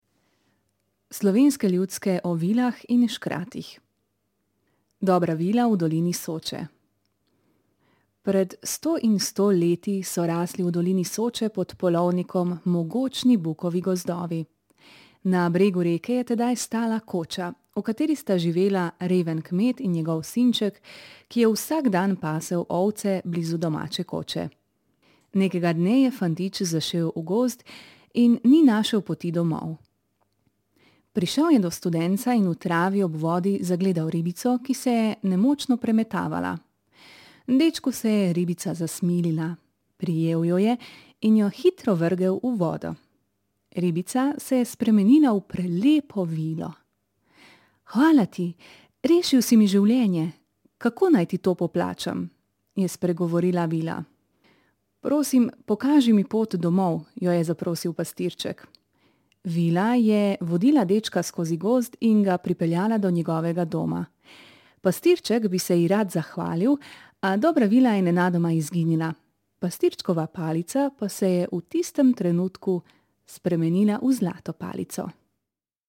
Zvočne pravljice